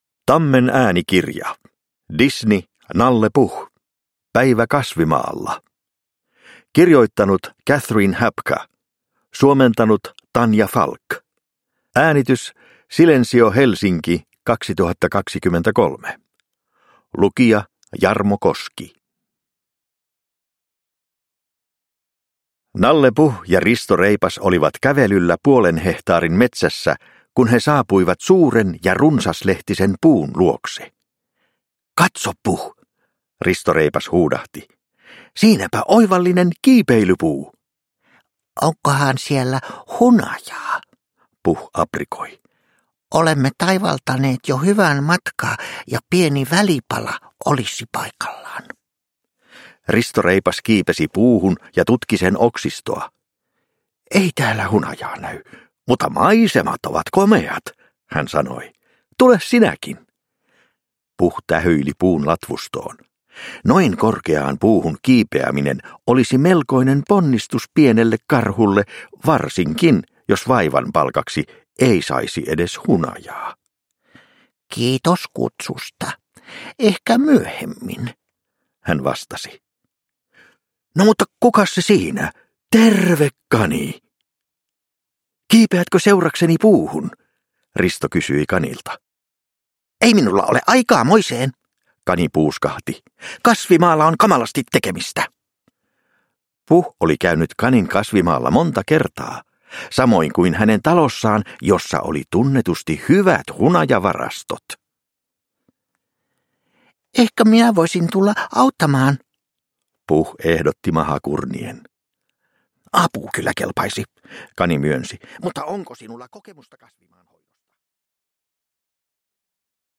Disney. Nalle Puh. Päivä kasvimaalla – Ljudbok – Laddas ner
Produkttyp: Digitala böcker